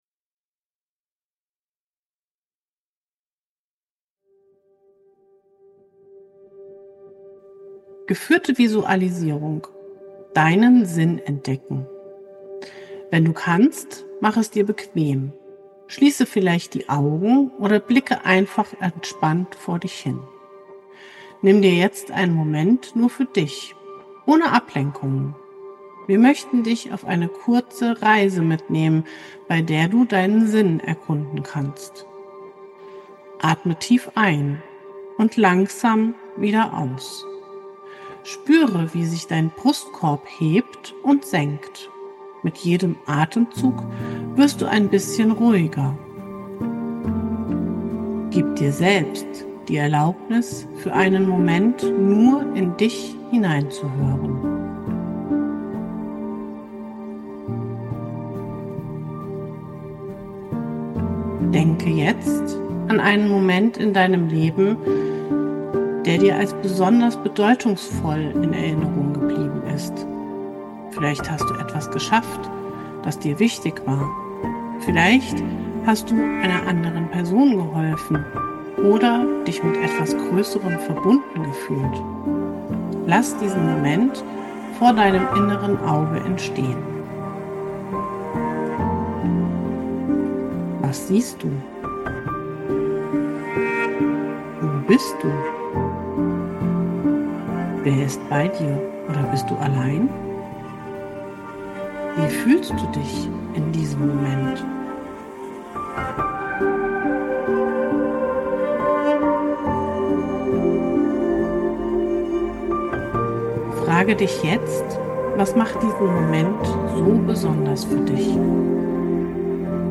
geführte Visualisierung